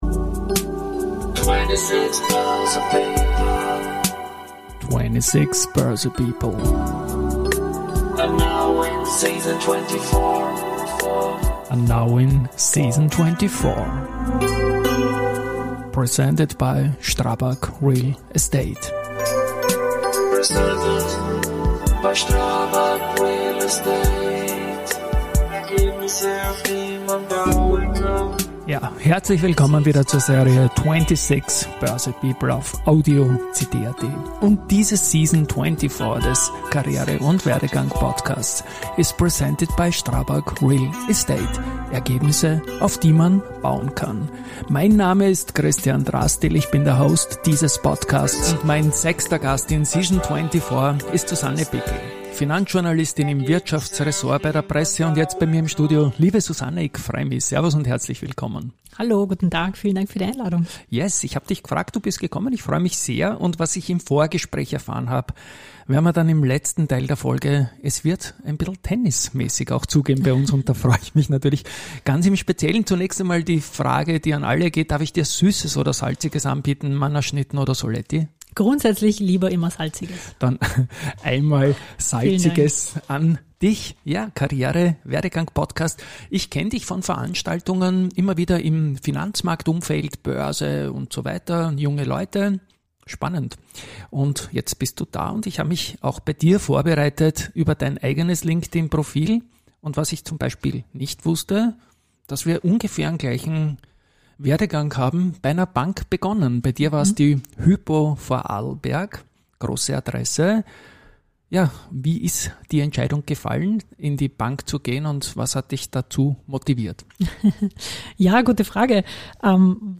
Es handelt sich dabei um typische Personality- und Werdegang-Gespräche.